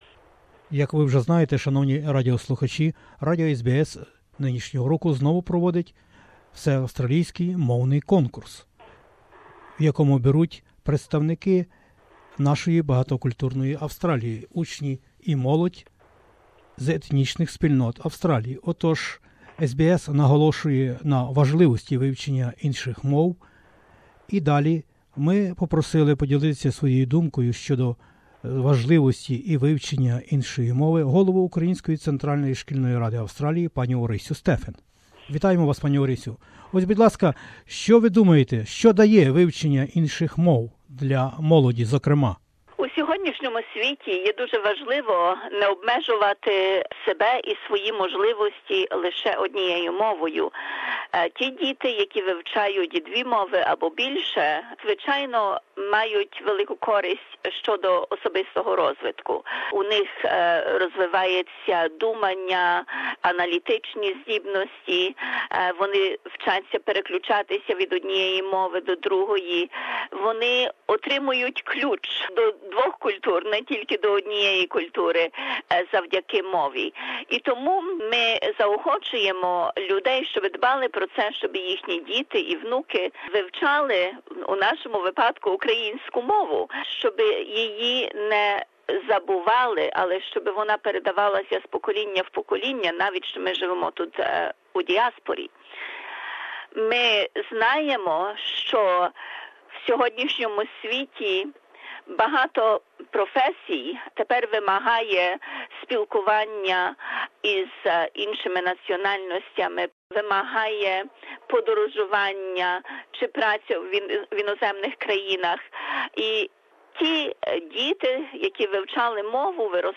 інтерв'ю